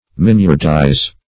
Miniardize \Min"iard*ize\, v. t. To render delicate or dainty.